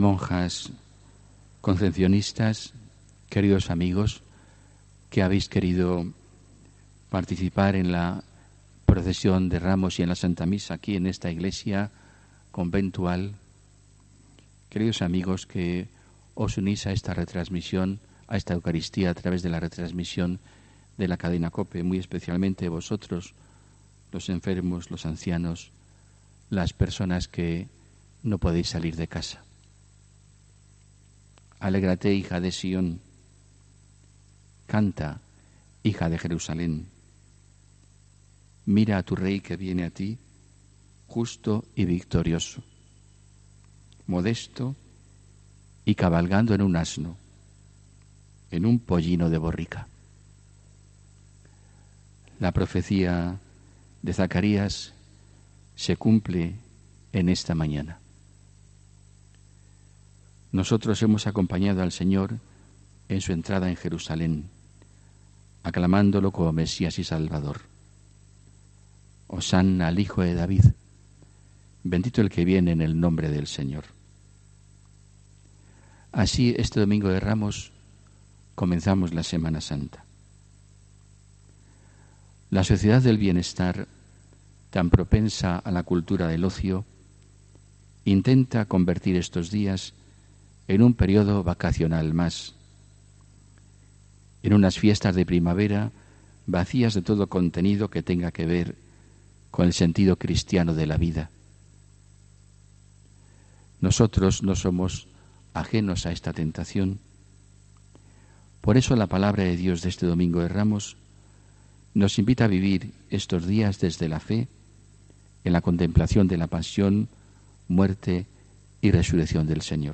HOMILÍA 14 ABRIL 2019